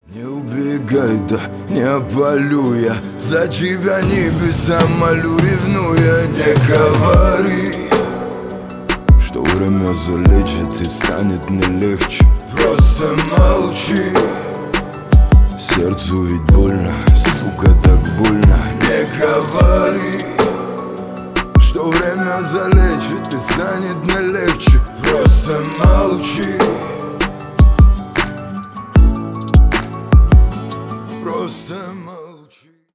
Поп Музыка
грустные # спокойные # тихие